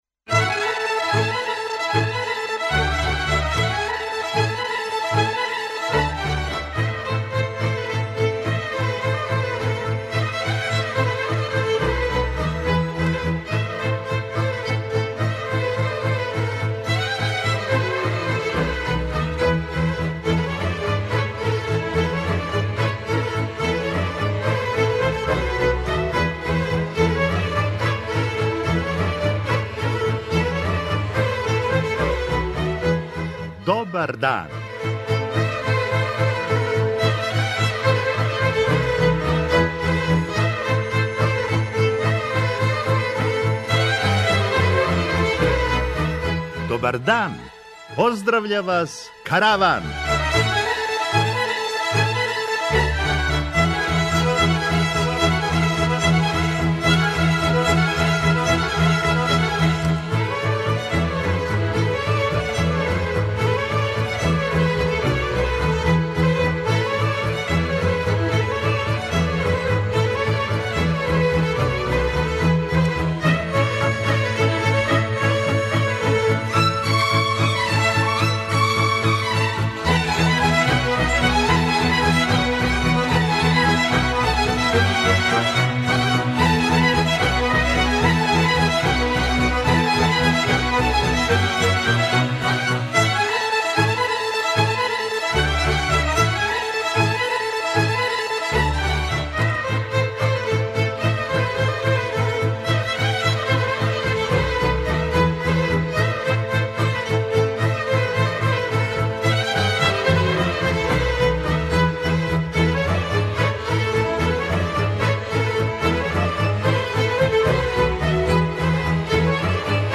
Слушаћете духовите одговоре на наше прошлонедељно питање: Шта нас очекује после повратка Војислава Шешеља?
преузми : 23.52 MB Караван Autor: Забавна редакција Радио Бeограда 1 Караван се креће ка својој дестинацији већ више од 50 година, увек добро натоварен актуелним хумором и изворним народним песмама.